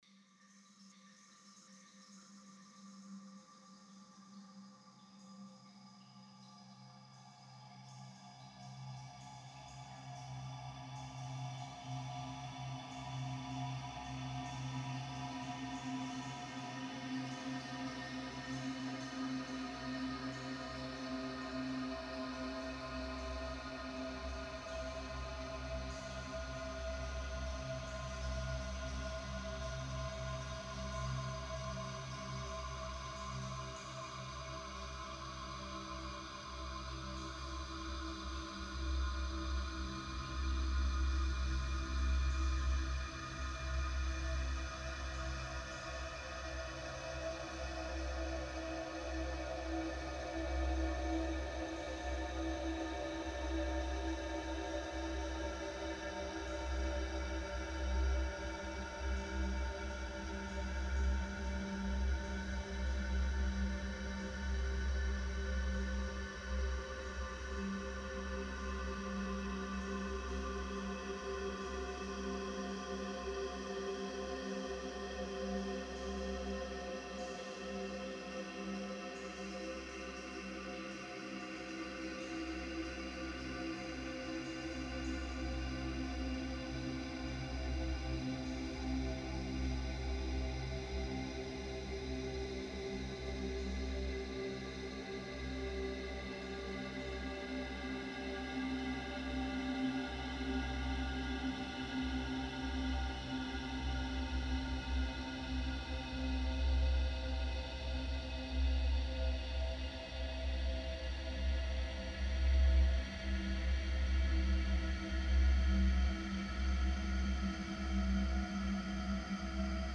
space-drone.mp3